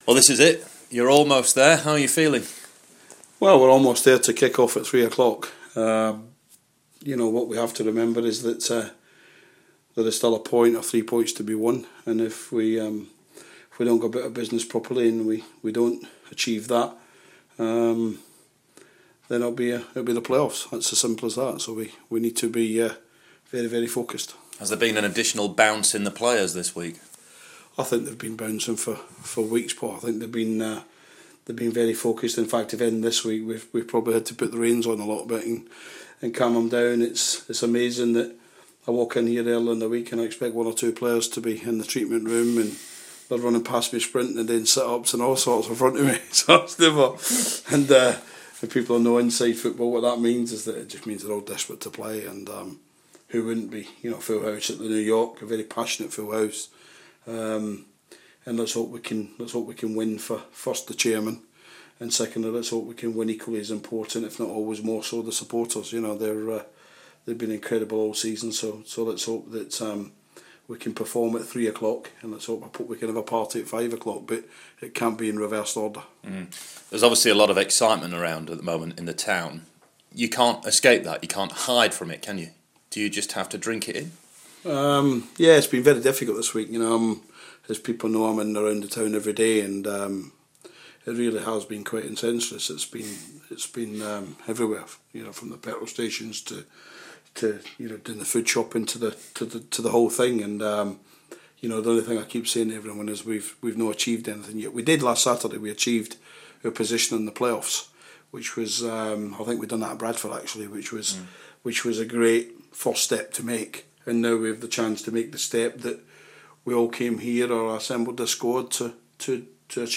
Millers boss Steve Evans gives his thoughts on what could be a promotion winning weekend for his side